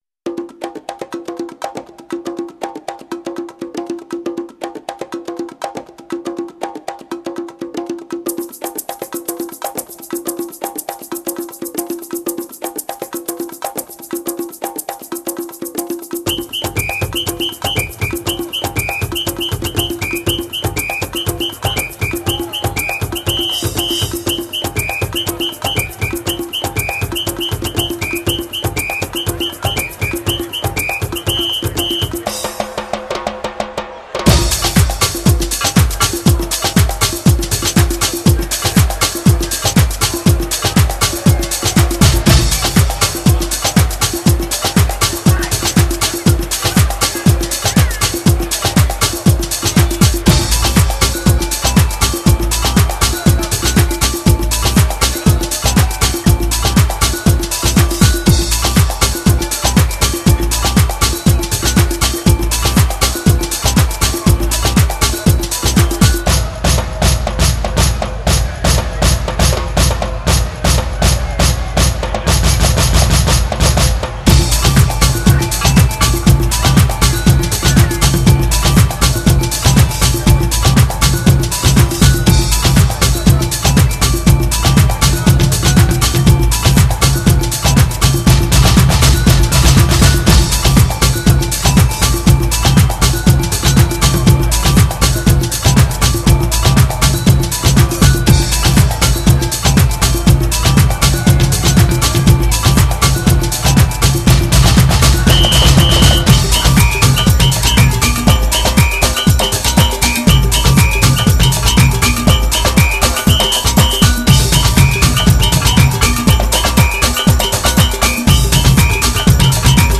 08 Samba